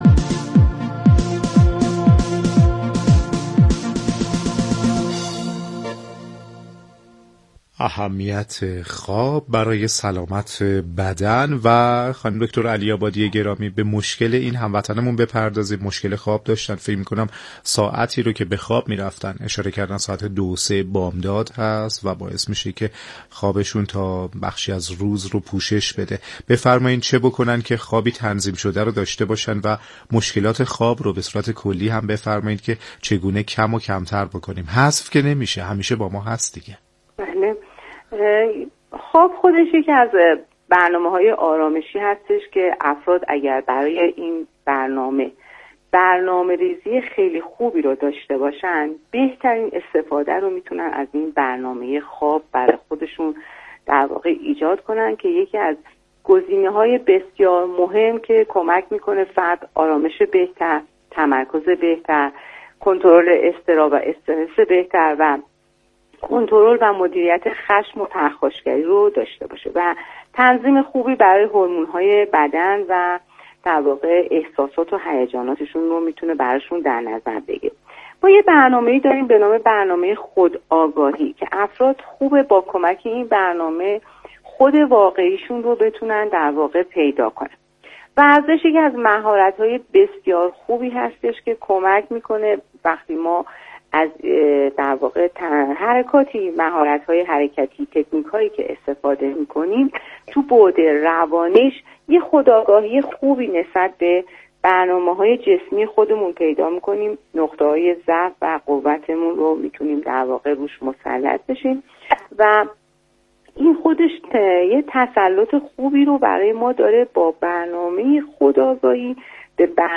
/صوت آموزشی/